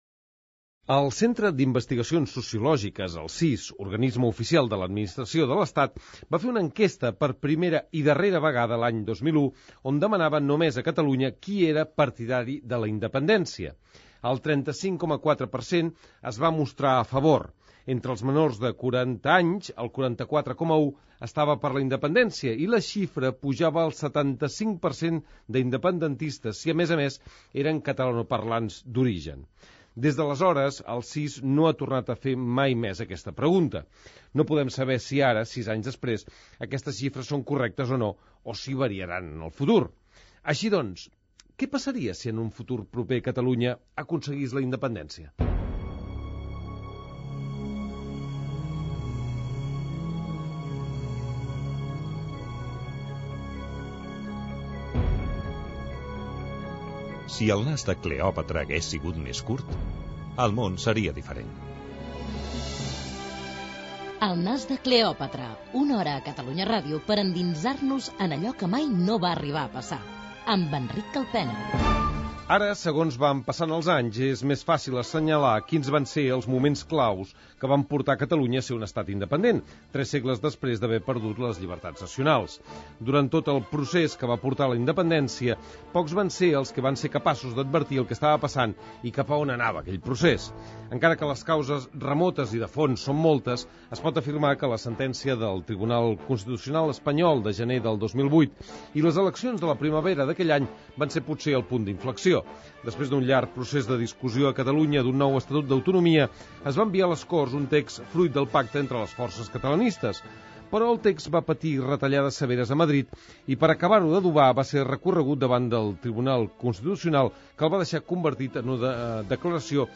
Careta del programa. Ficció històrica de com Catalunya va arribar a ser independent el dia 1 de gener del 2016.
Indicatiu del programa i de l'emissora.